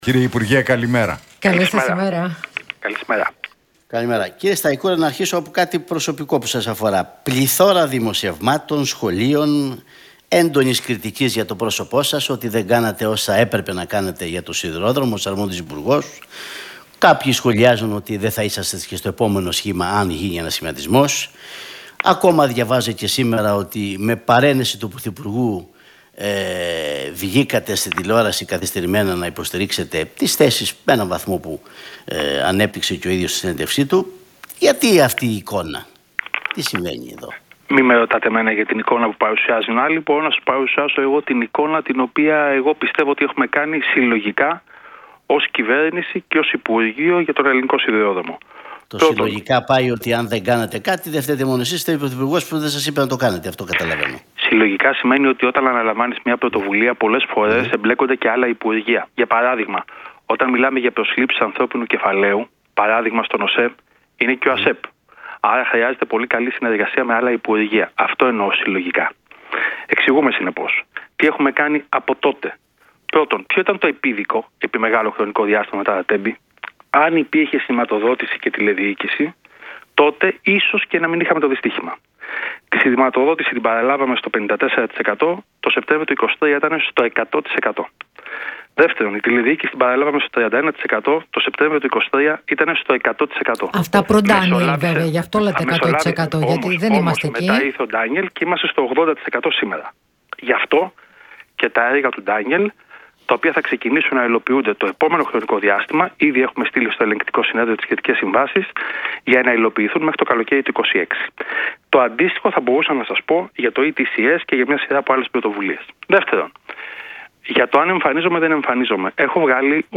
Σταϊκούρας στον Realfm 97,8: Η κατάσταση στον σιδηρόδρομο σήμερα είναι πιο ασφαλής από ό,τι ήταν τότε - Τι είπε για τα Τέμπη και την Hellenic Train